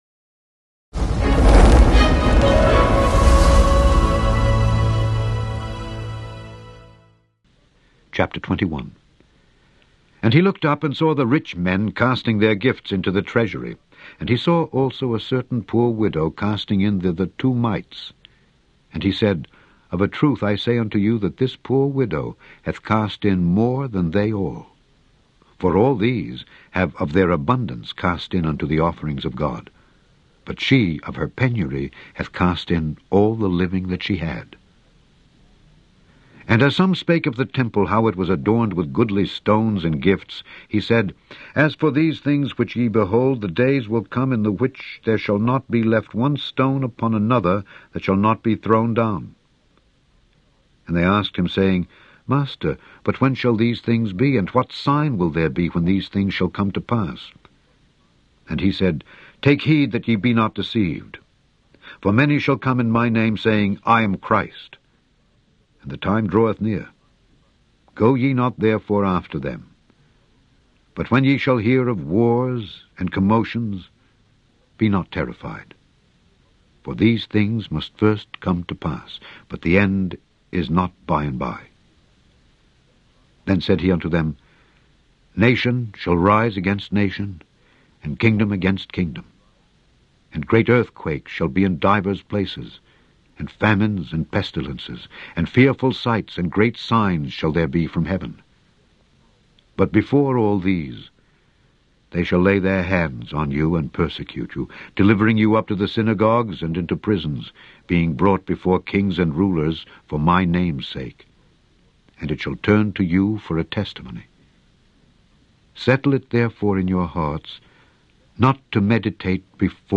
In this podcast, you can hear Alexander Scourby read Luke 21-22.